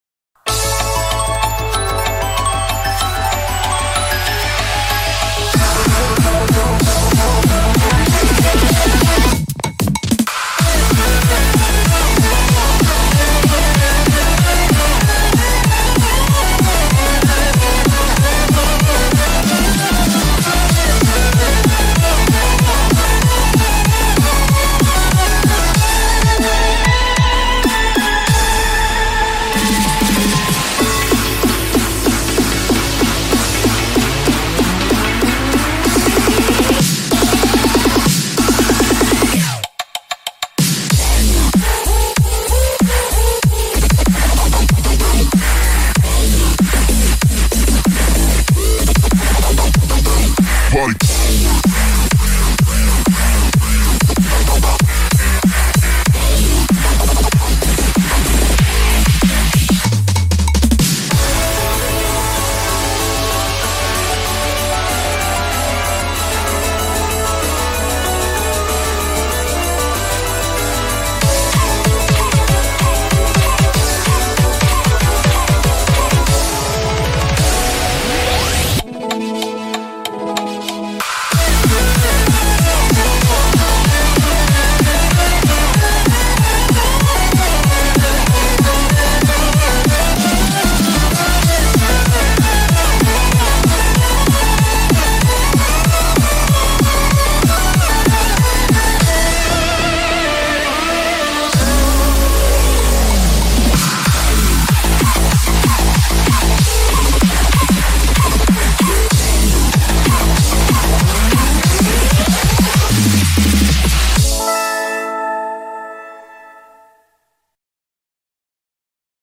BPM95-190
Audio QualityPerfect (Low Quality)